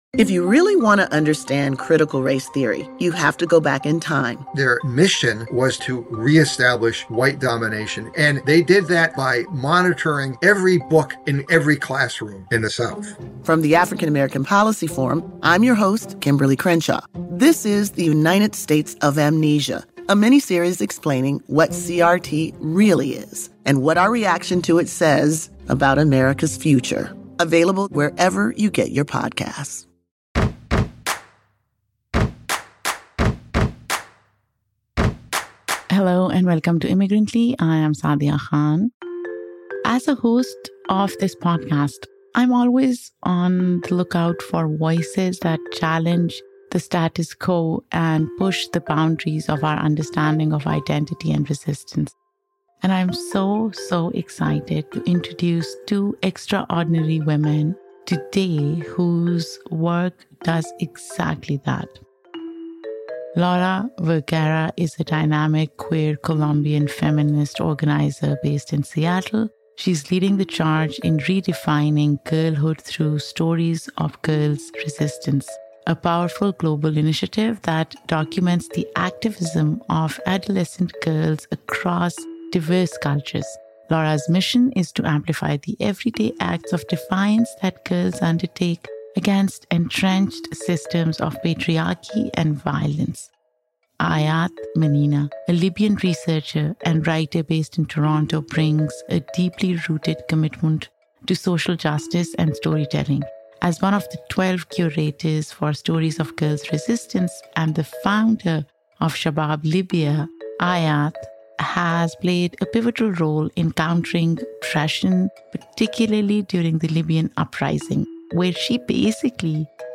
In this episode, I’m thrilled to introduce two extraordinary women whose work does precisely that.
Together, we explore the power of collective action and the role of immigrant experiences in shaping their activism. This conversation goes beyond celebrating resistance; it imagines a future where girls live freely, unburdened by the fight for their fu...